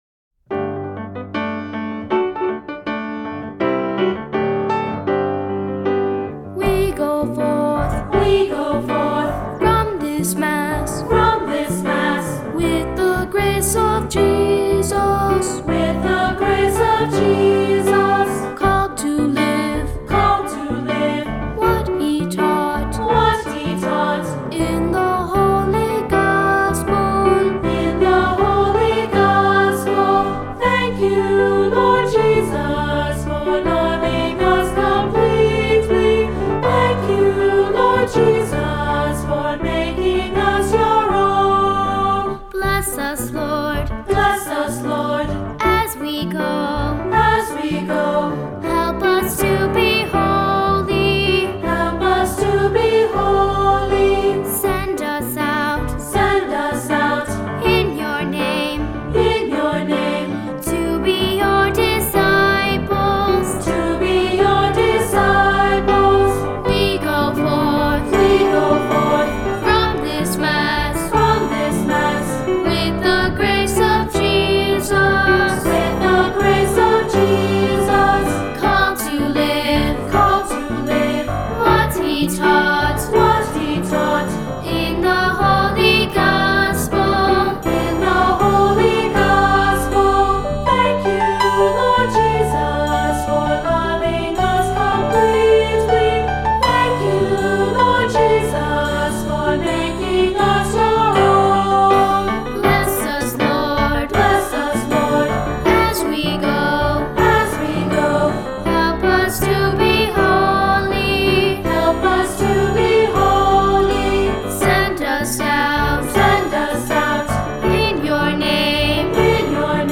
Voicing: Unison Children’s Choir, Cantor, Assembly